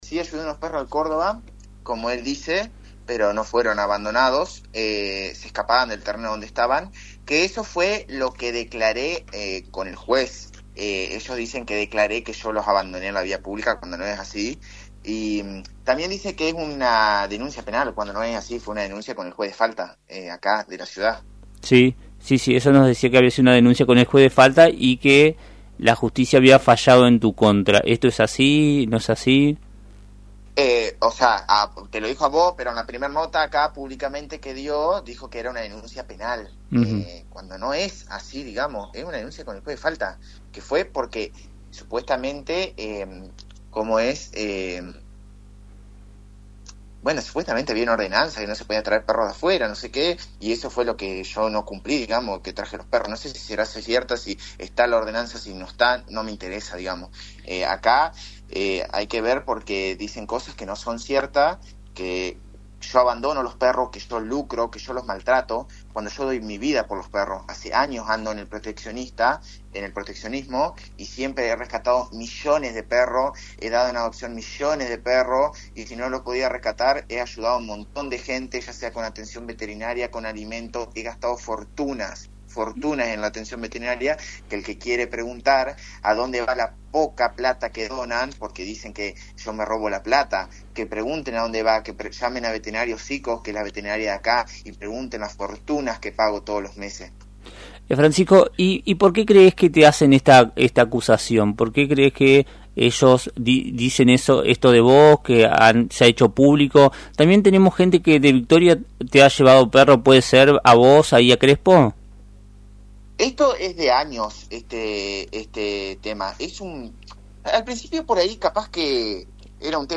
En diálogo con el programa “Puntos Comunes” de am 980, explicó que la denuncia en su contra no es penal, sino que fue presentada ante el juez de faltas de la ciudad de Crespo por supuestamente no cumplir con una ordenanza que prohíbe traer perros de afuera de la ciudad.